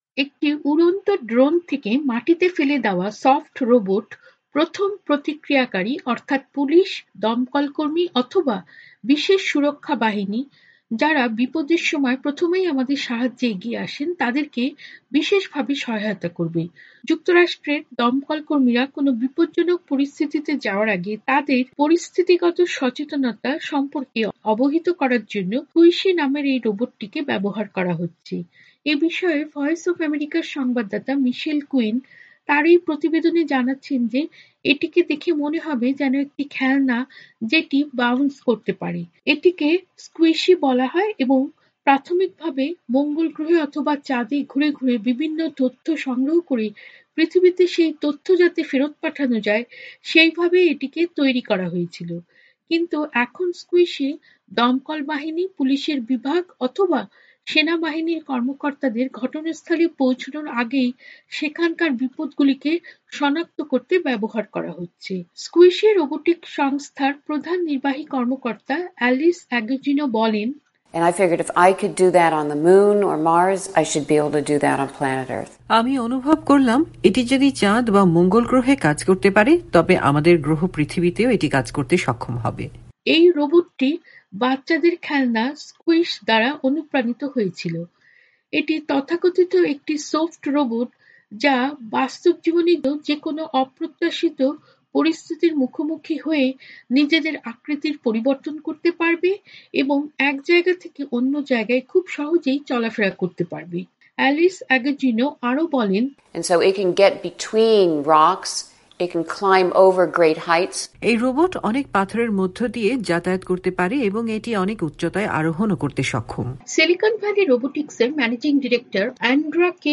প্রতিবেদনের বাংলা রুপান্তর শোনাচ্ছেন